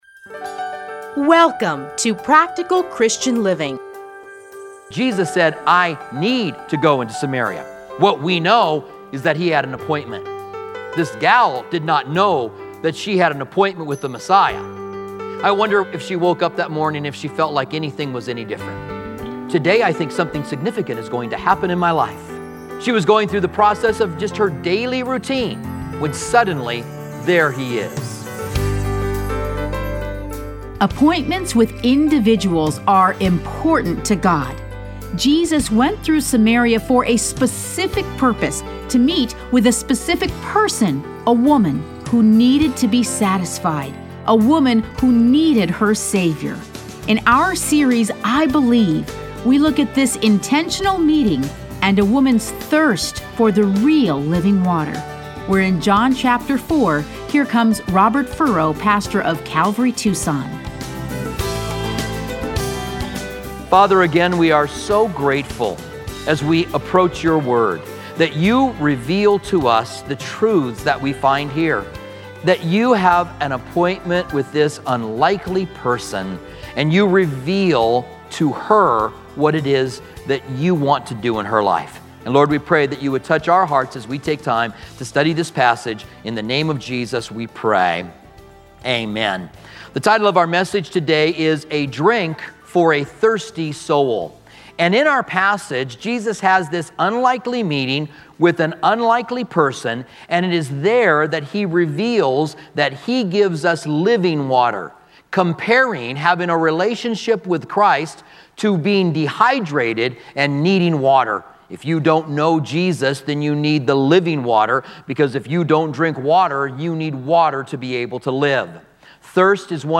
Listen to a teaching from John 4:1-29.